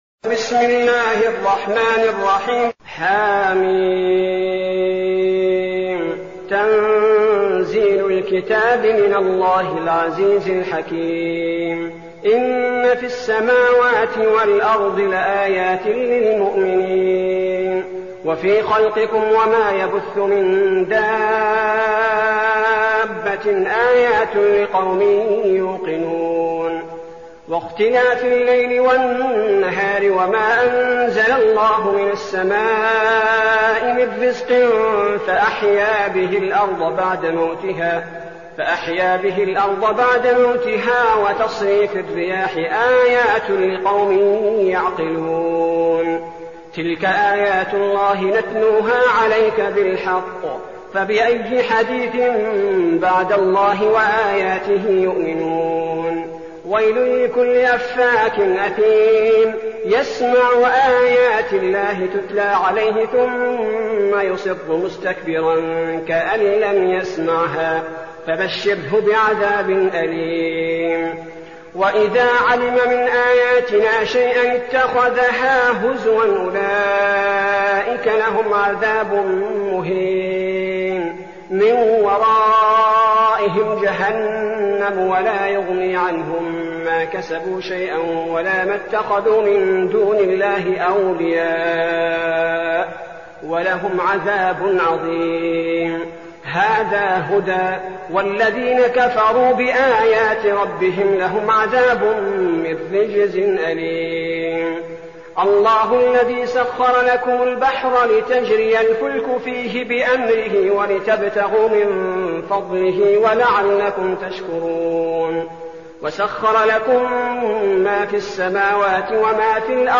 المكان: المسجد النبوي الشيخ: فضيلة الشيخ عبدالباري الثبيتي فضيلة الشيخ عبدالباري الثبيتي الجاثية